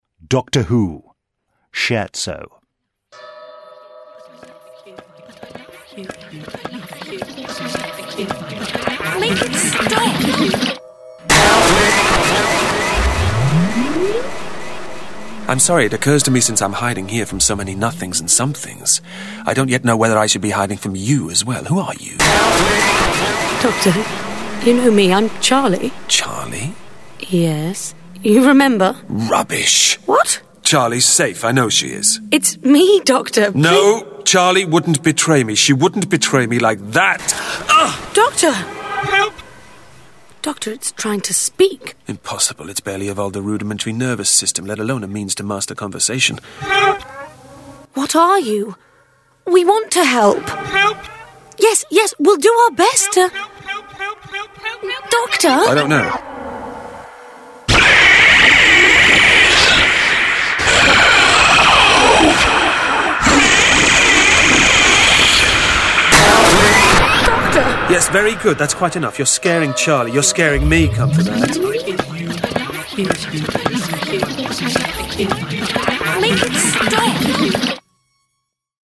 Award-winning, full-cast original audio dramas